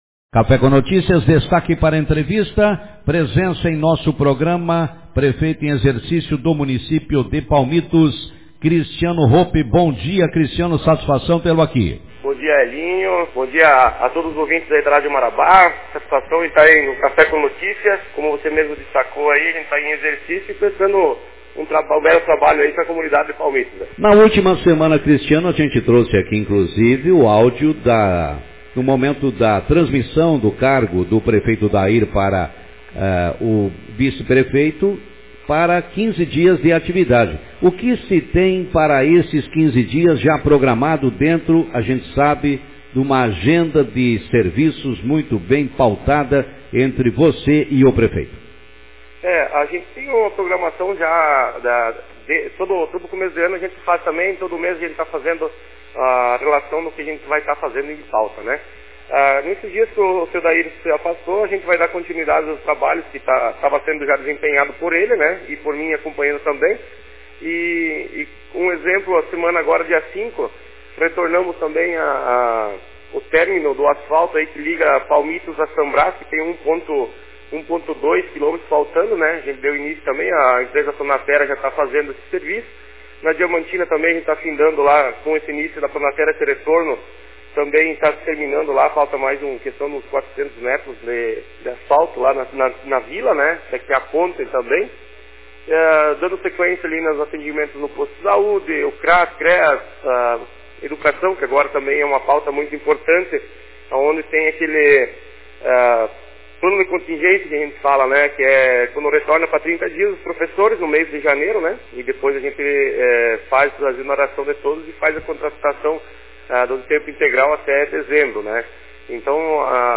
Vice-prefeito de Palmitos destaca as ações para as próximas semanas Autor: Rádio Marabá 09/01/2023 Manchete Na manhã desta segunda-feira, o vice-prefeito de Palmitos, Cristiano Hoppe, participou do programa Café com Notícias e destacou as ações que deve realizar durante os 15 dias em que estará à frente do Executivo Municipal. Acompanhe a entrevista